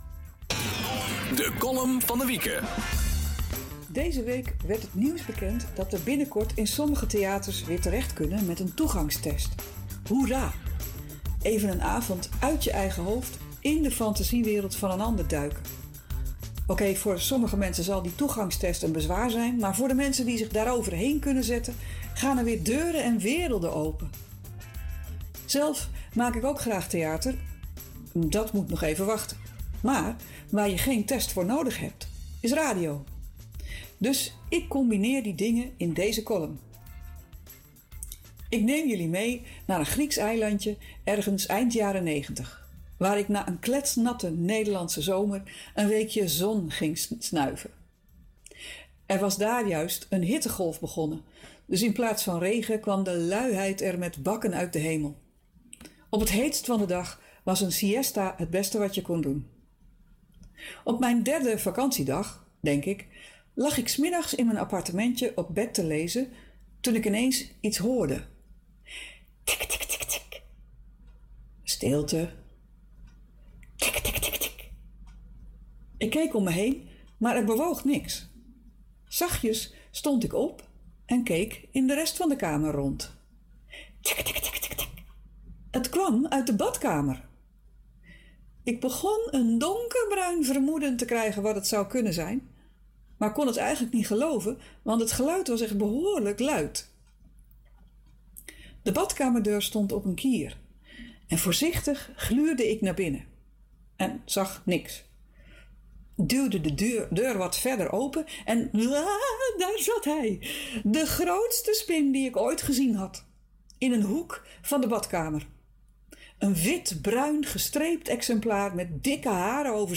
COLUMN: ‘Nelis’